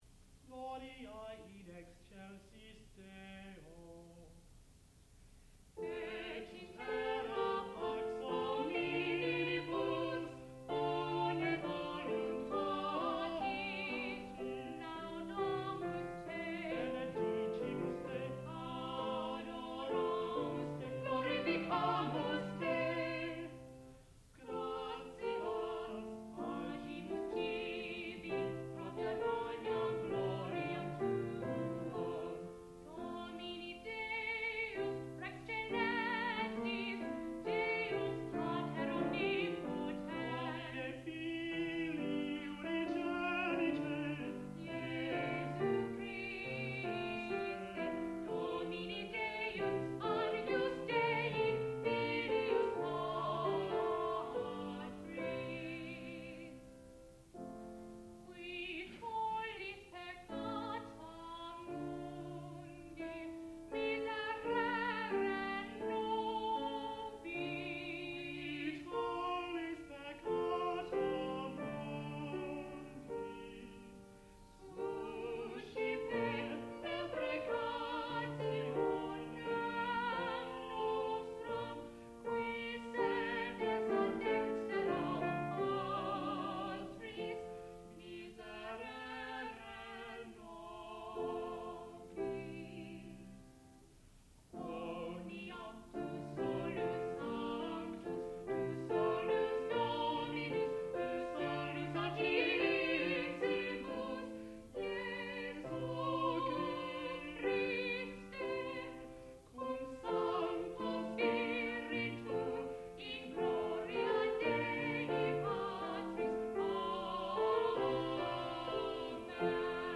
sound recording-musical
classical music
piano
tenor